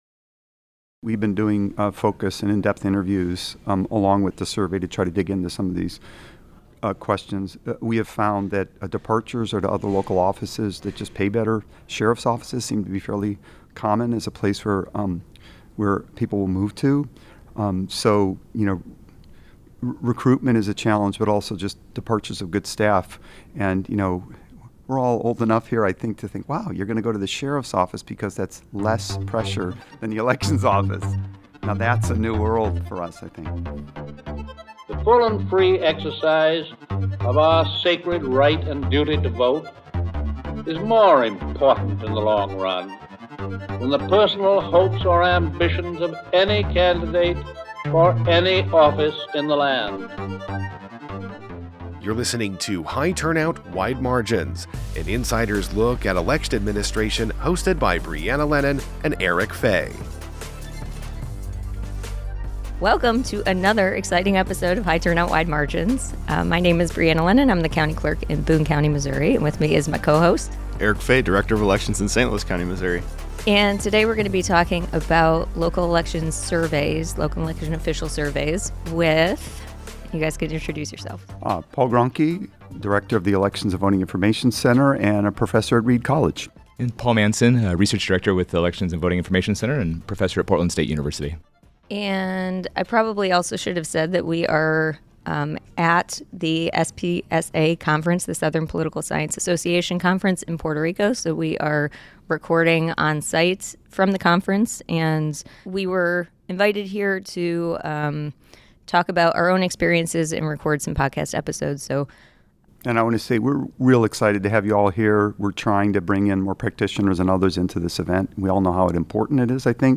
In January, the HTWM team was invited to record a live episode at the Southern Political Science Association meeting held in San Juan, Puerto Rico.